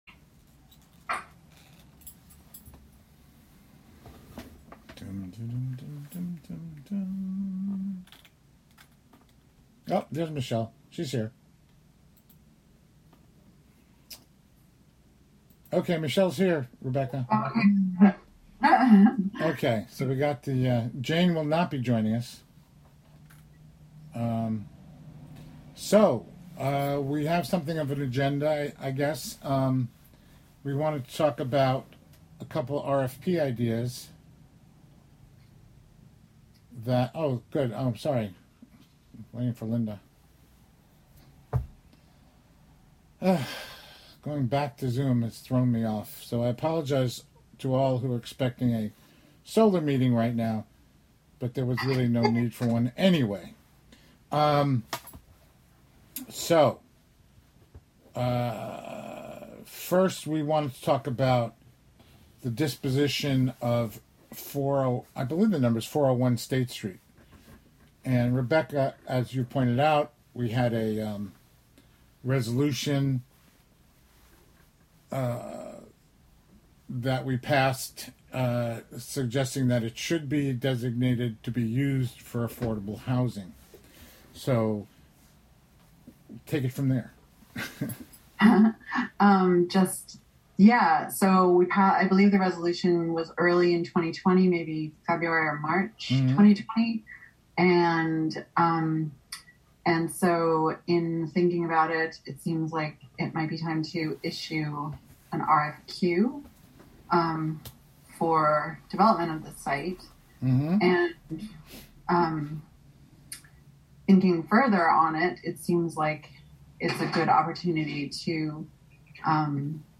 Live from the City of Hudson: Hudson Properties Committee (Audio)